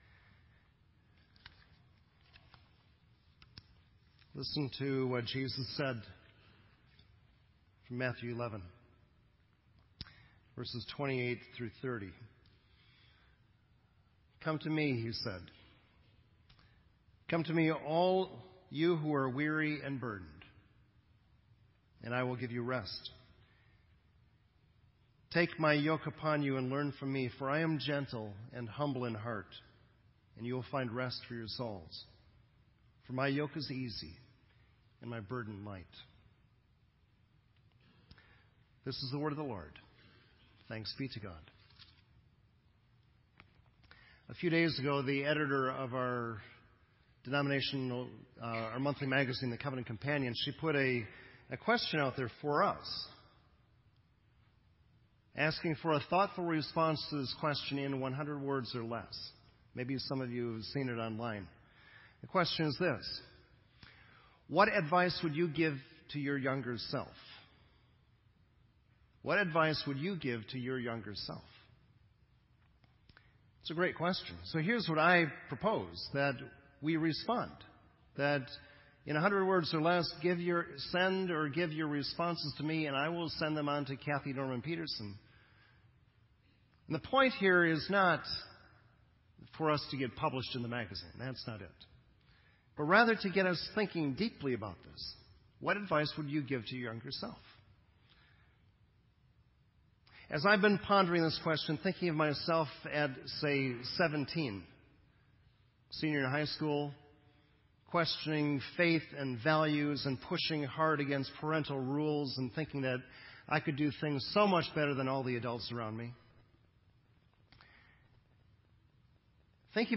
This entry was posted in Sermon Audio on November 17